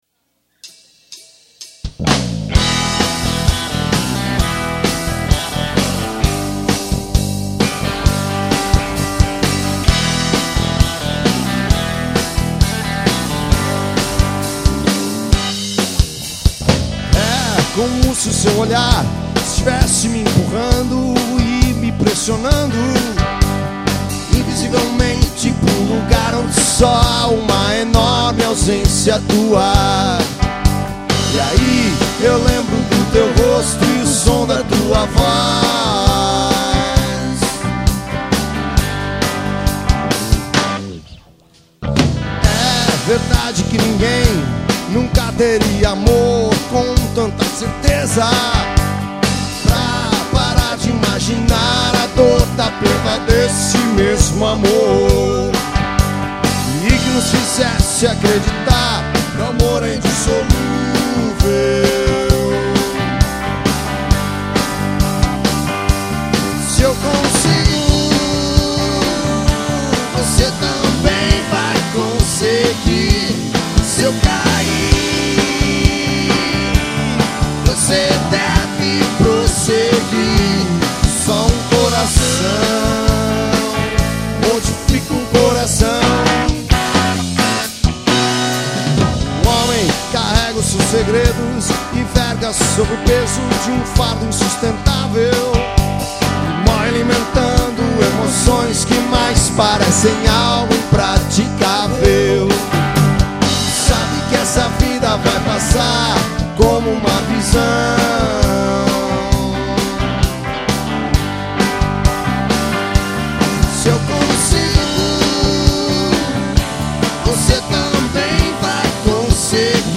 EstiloBlues